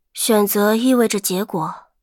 尘白禁区_安卡希雅语音_消灭2.mp3